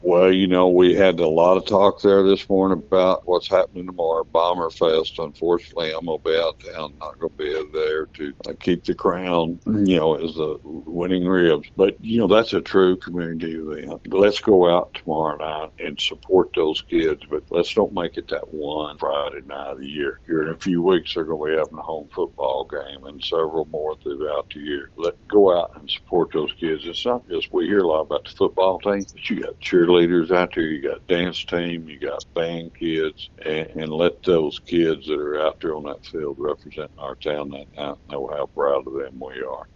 Following the event, Mayor Hillrey Adams shared key takeaways in an interview with KTLO, Classic Hits and The Boot News.